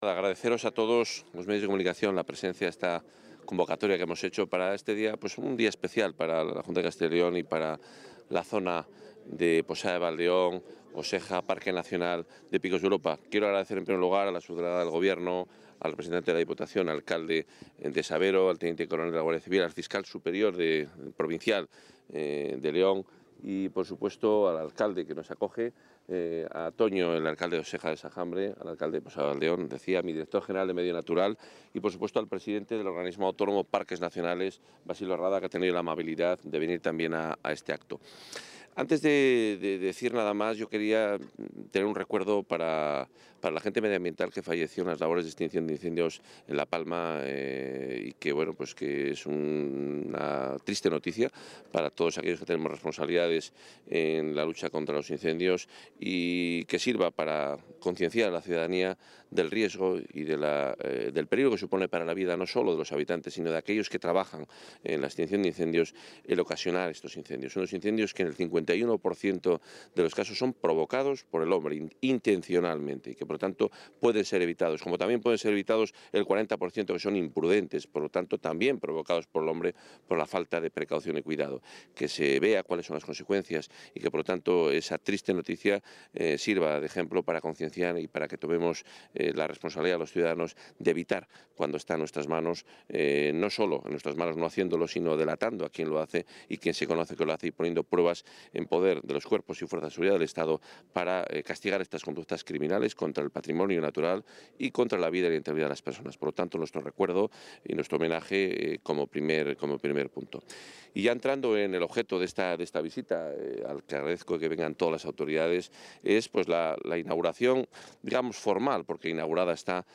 Audio consejero Fomento y Medio Ambiente.